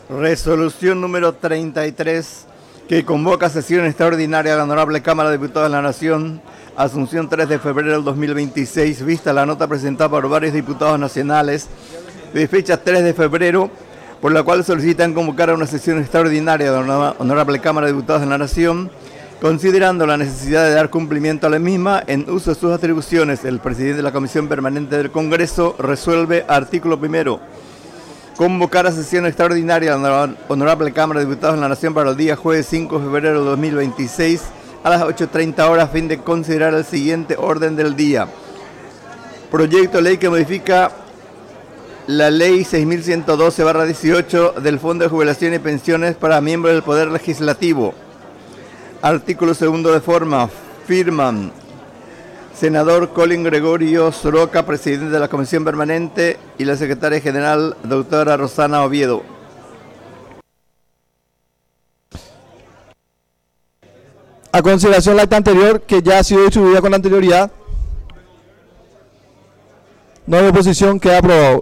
Sesión Extraordinaria, 5 de febrero de 2025